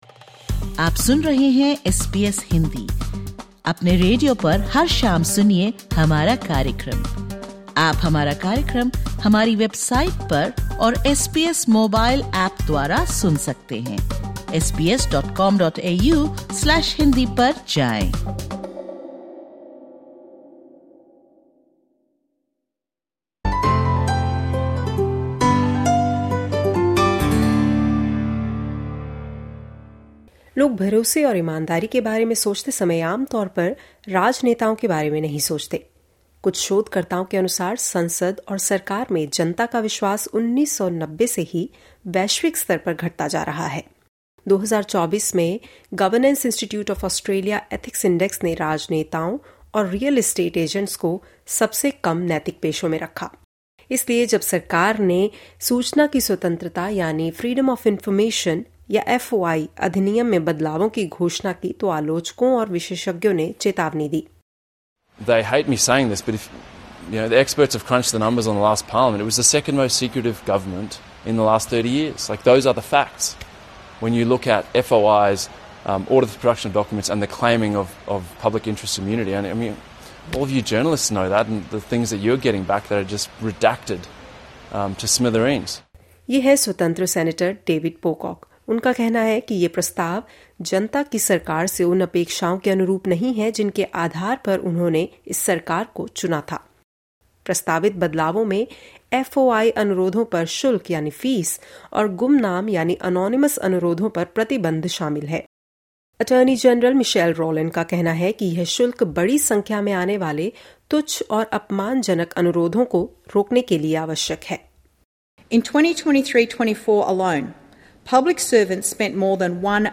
Critics say the Albanese government’s plan to amend Freedom of Information laws will curb public access to documents and expand secrecy, undermining public trust at a time when faith in politicians is already at historic lows. More in this report.